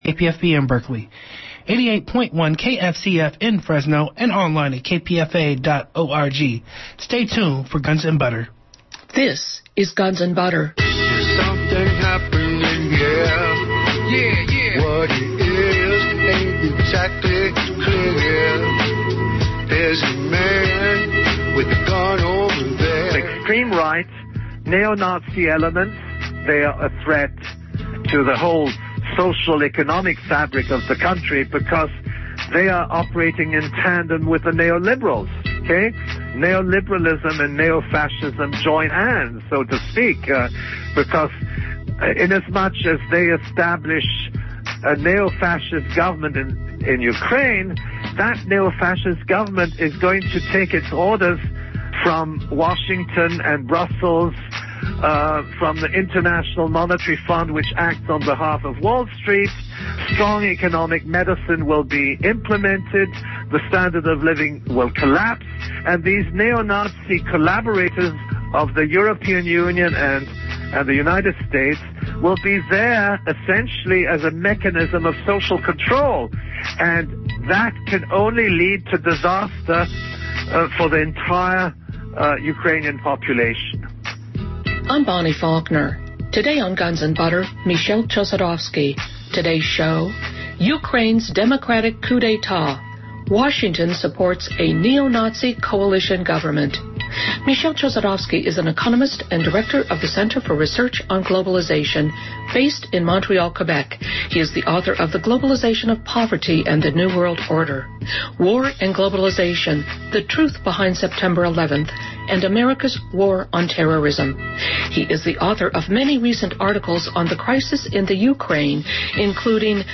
Transcript of Interview